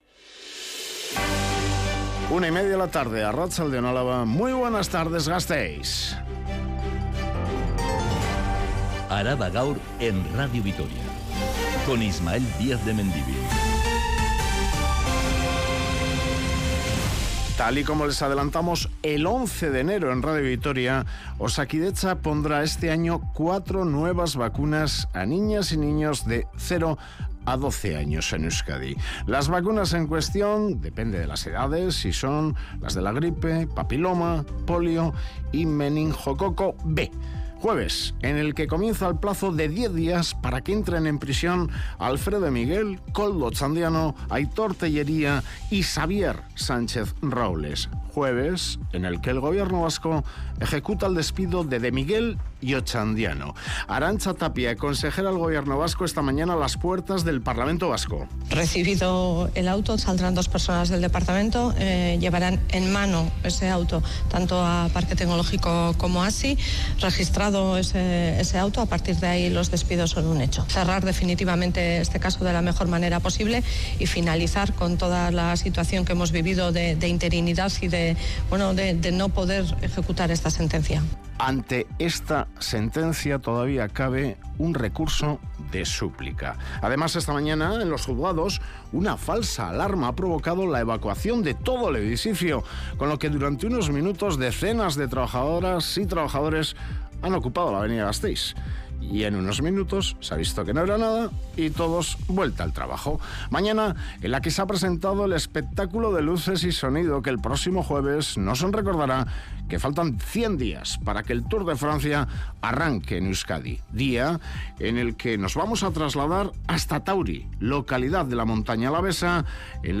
Toda la información de Álava y del mundo.